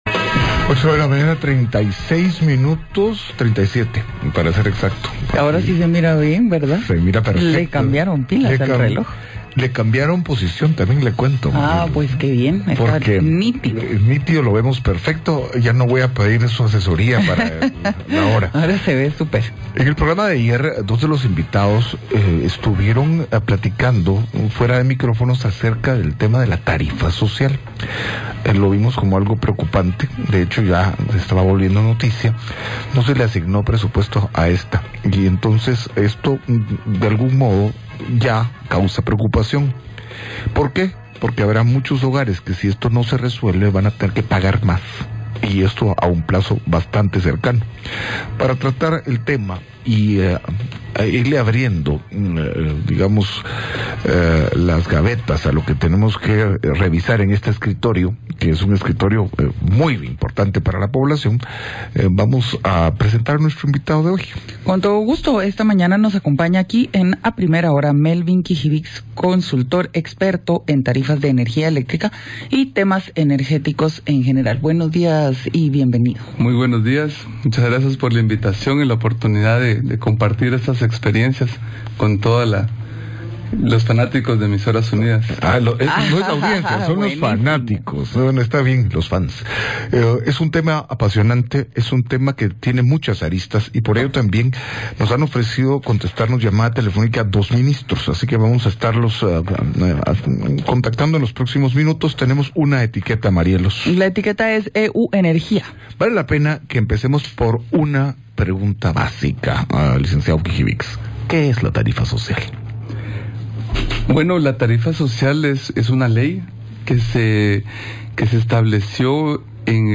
A PRIMERA HORA / EMISORAS UNIDAS: Entrevista